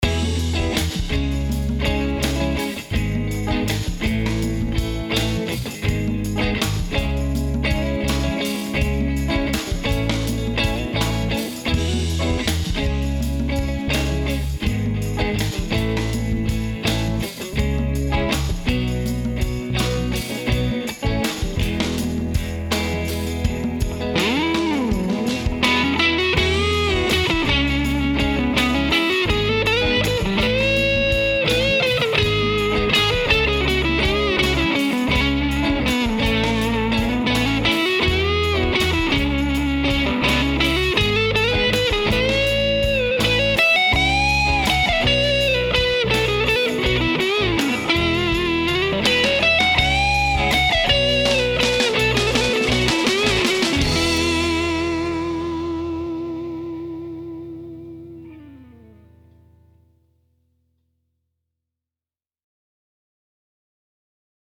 The overall tone also brightens up significantly, with a definite emphasis on the midrange, which I love.
Here’s a clip that I recorded to demonstrate the VRX22, but the P12N was used in all guitar parts. The rhythm parts were played through an open-back 1 X 12 cabinet, while the lead was played with the back of the cabinet closed: